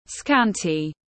Ít ỏi tiếng anh gọi là scanty, phiên âm tiếng anh đọc là /ˈskæn.ti/ .
Scanty /ˈskæn.ti/